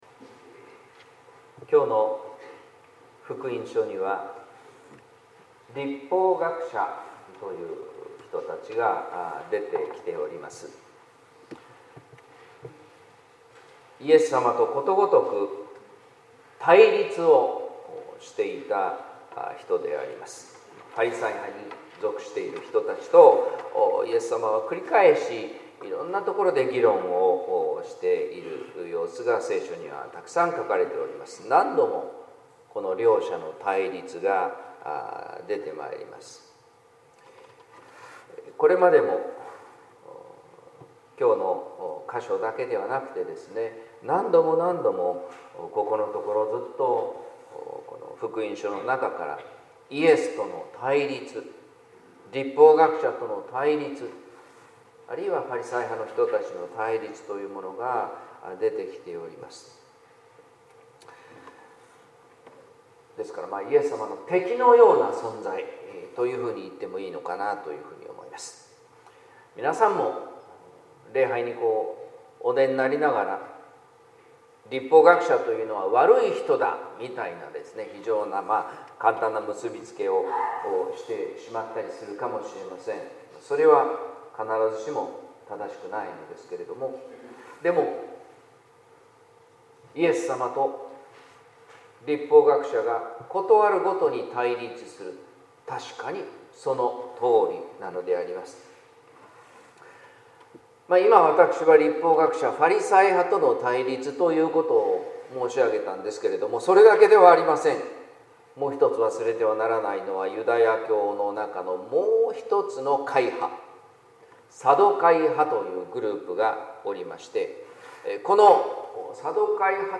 説教「あなたは遠くない」（音声版） | 日本福音ルーテル市ヶ谷教会